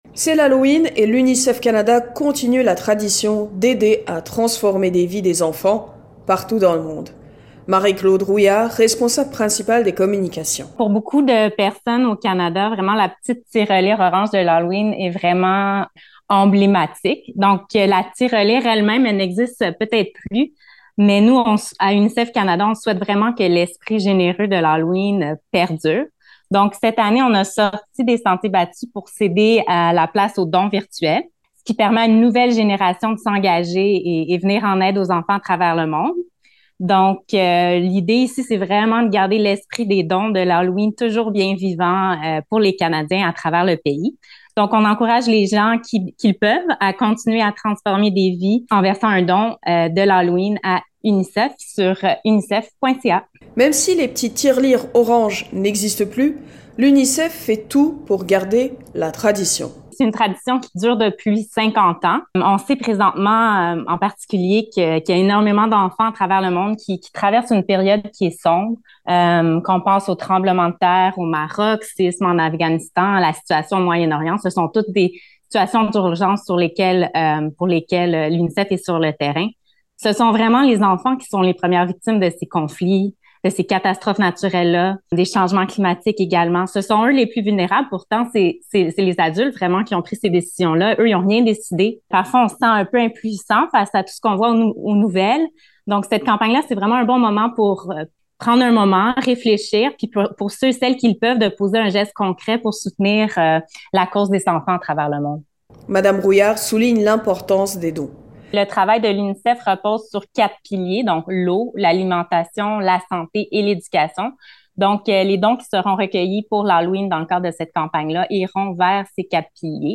Voici l’entrevue